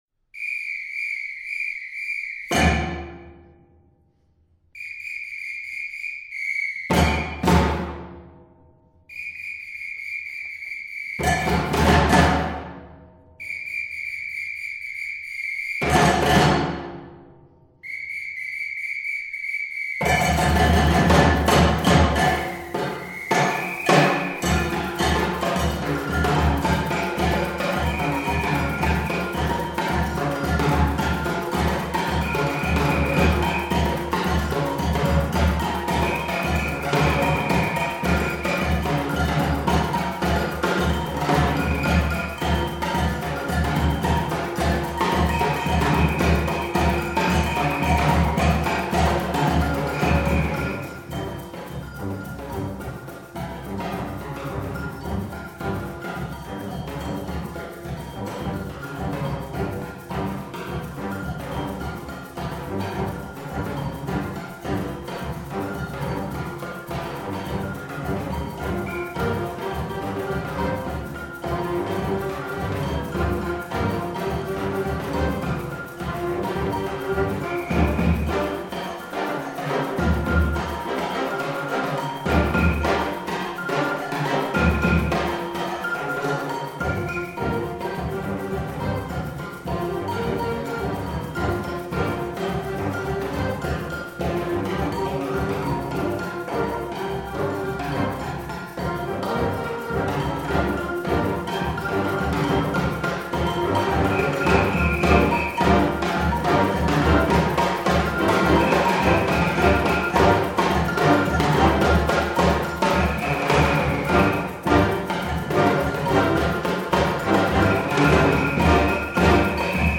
Voicing: Band